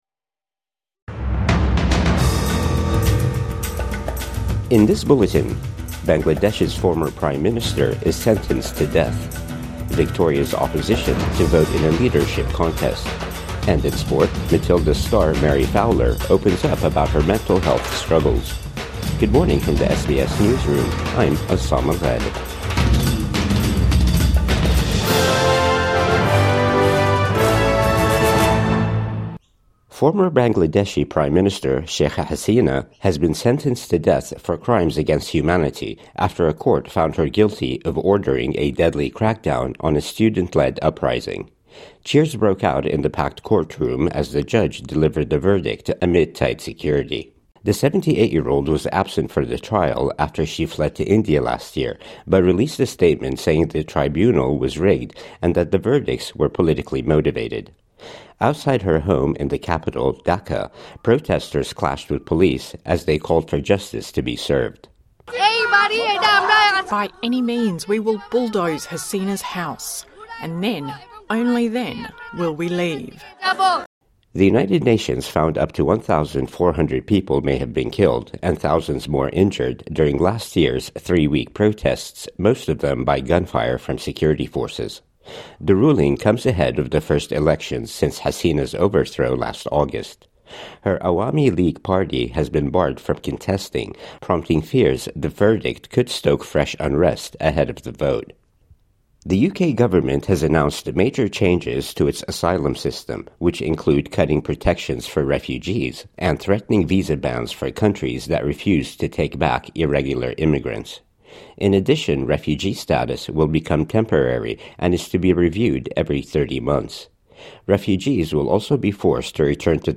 Bangladesh's former Prime Minister sentenced to death | Morning News Bulletin 18 November 2025